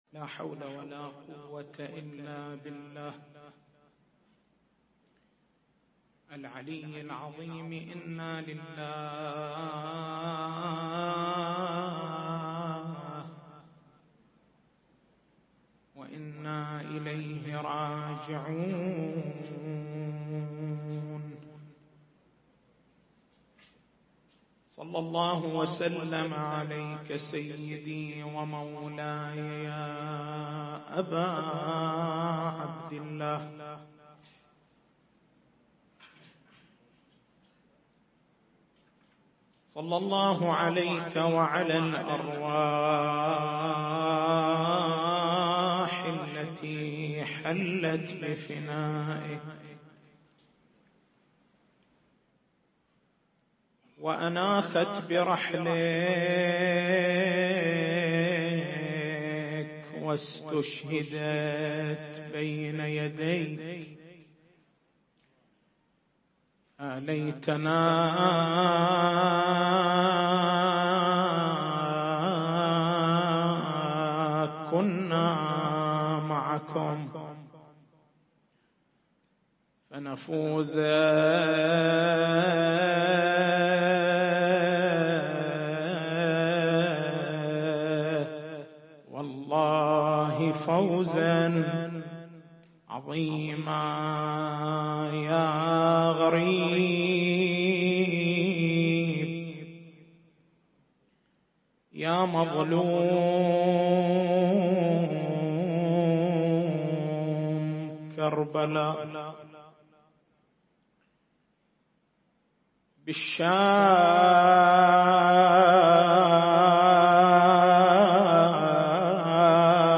تاريخ المحاضرة: 05/02/1437 نقاط البحث: ما هو معنى اسم (رقيّة)؟